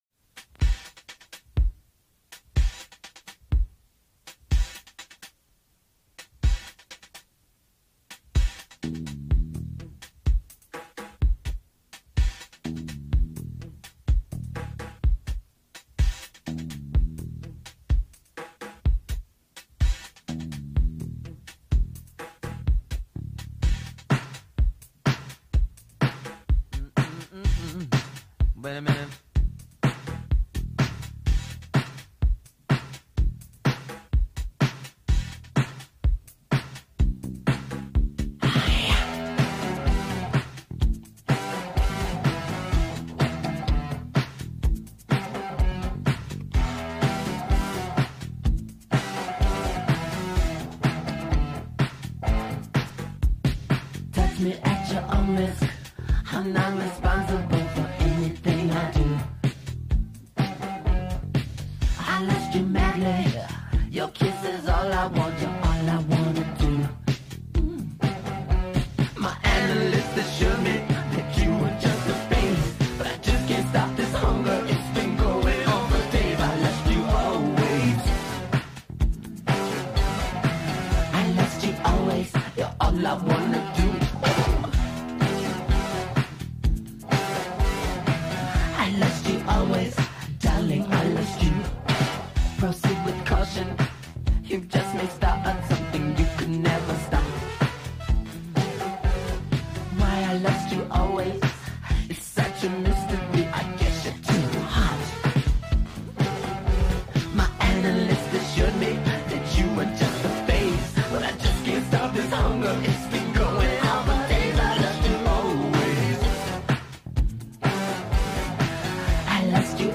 Home Studio Sessions 1982